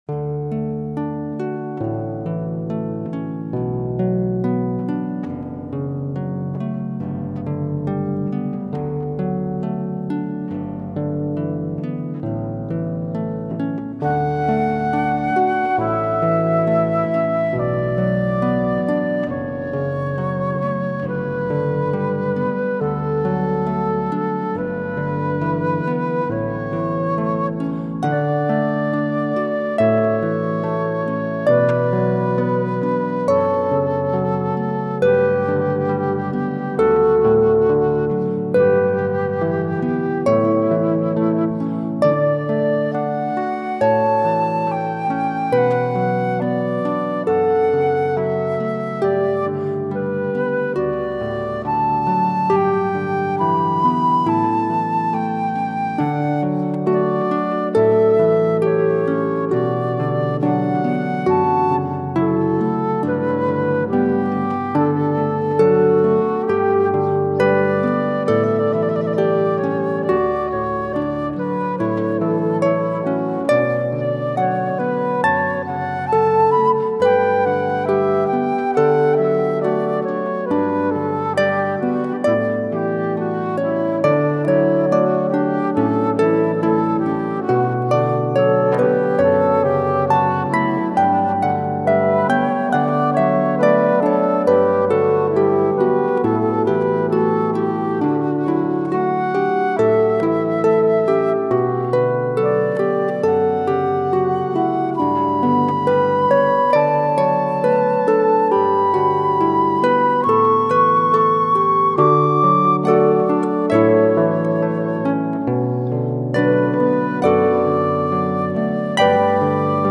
Harpist